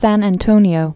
(săn ăn-tōnē-ō)